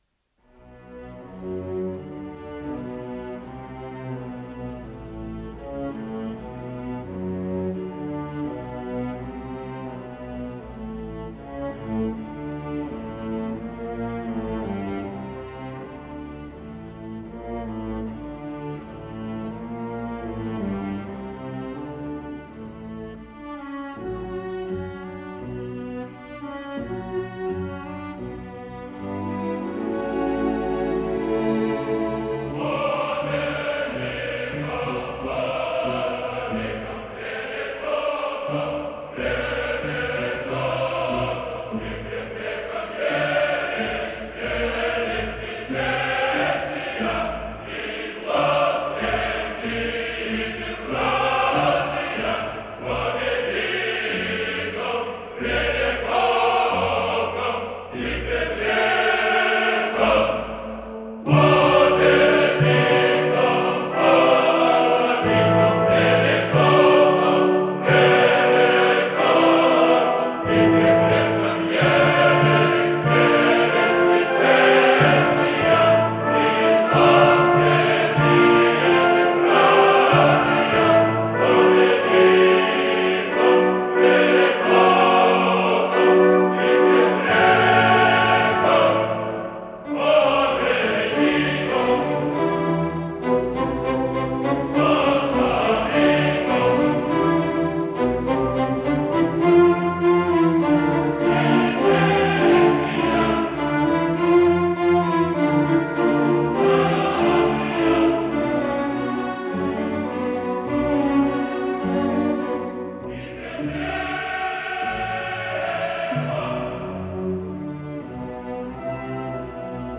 Исполнитель: Солисты, хор и оркестр Государственного академического Большого театра СССР
Название передачи Хованщина Подзаголовок Народная музыкальная драма в 5-ти действиях, 6-ти картинах Код ПКС-025655 Фонд Без фонда (ГДРЗ) Редакция Музыкальная Общее звучание 02:42:21 Дата записи 17.10.1988 Дата добавления 13.10.2024 Прослушать